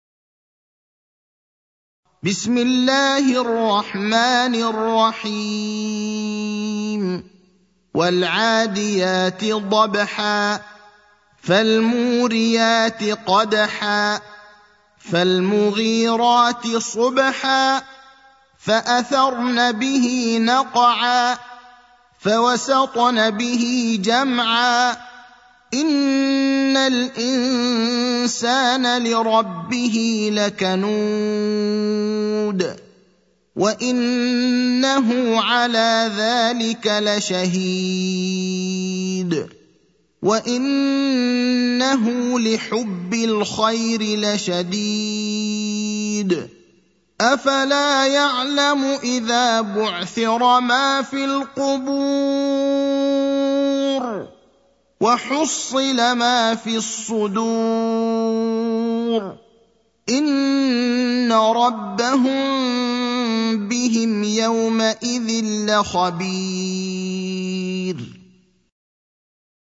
المكان: المسجد النبوي الشيخ: فضيلة الشيخ إبراهيم الأخضر فضيلة الشيخ إبراهيم الأخضر العاديات (100)